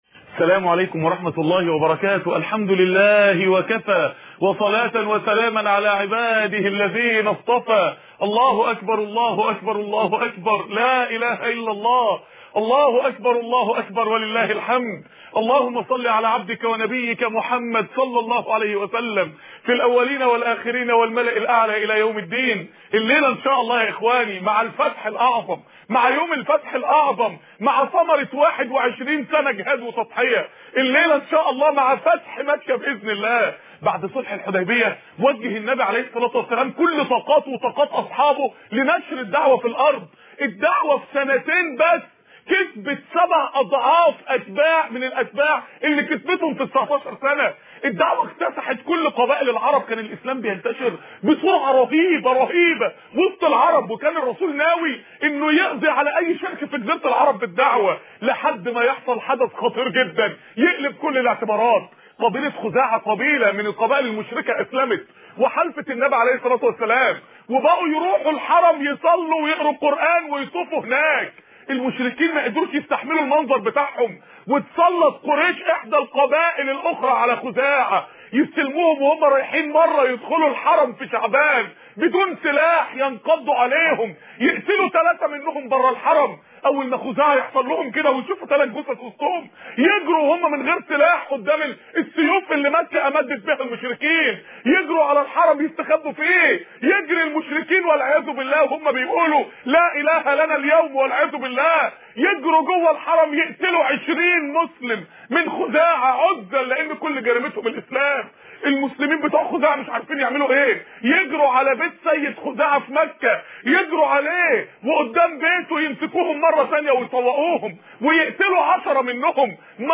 السنة المطهرة خطب ومحاضرات لماذا محمد ؟